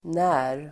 Uttal: [nä:r]